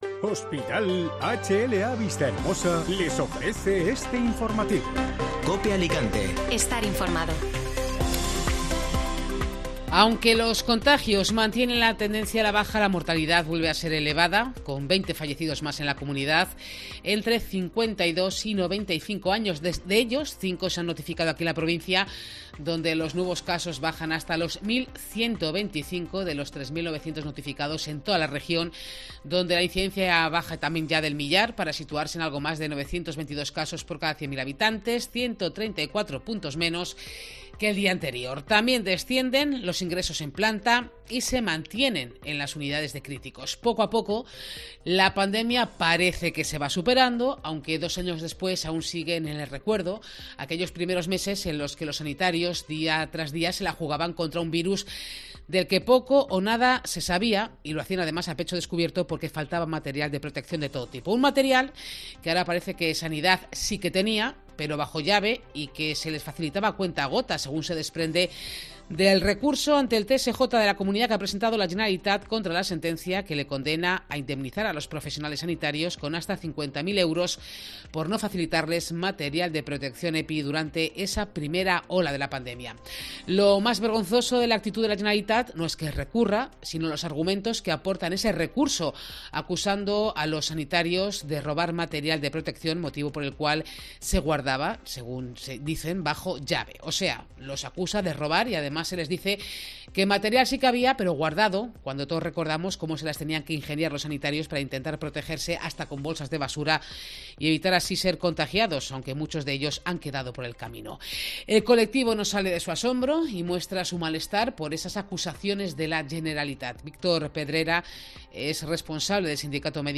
Informativo Mediodía COPE (Miércoles 23 de febrero)